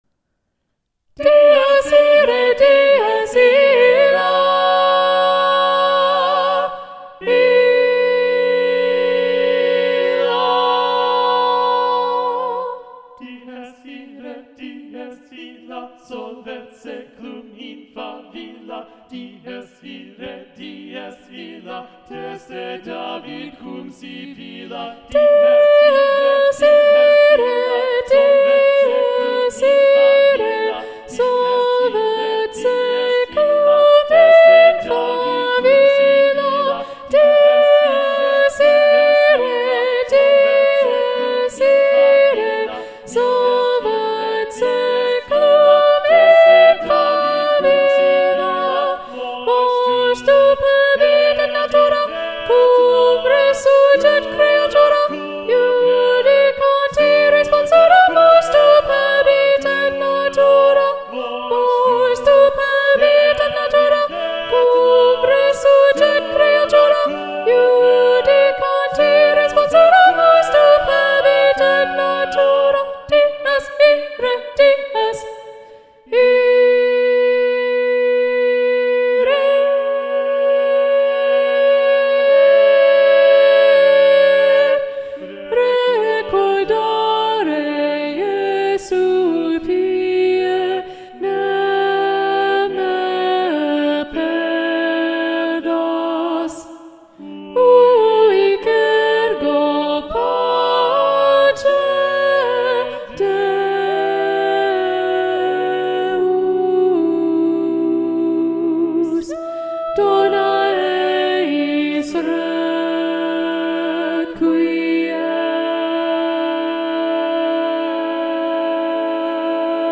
- Œuvre pour chœur à 8 voix mixtes (SSAATTBB)
SATB Soprano 2 Predominant